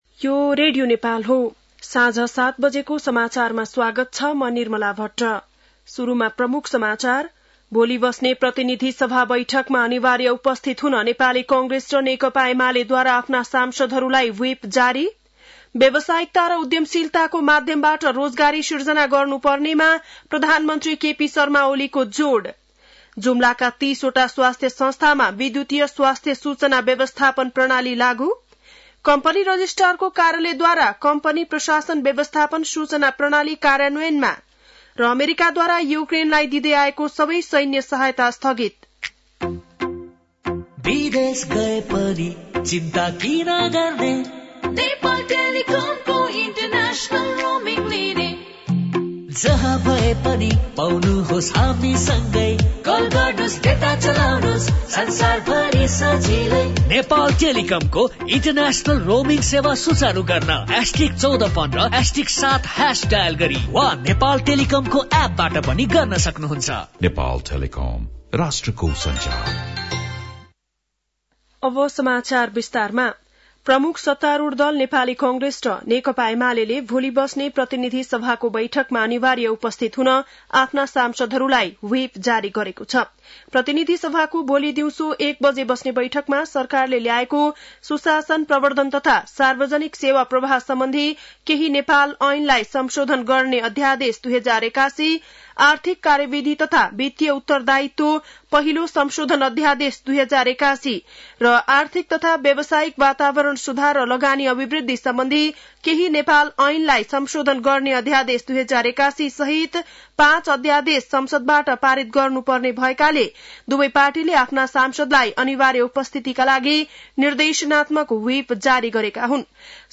बेलुकी ७ बजेको नेपाली समाचार : २१ फागुन , २०८१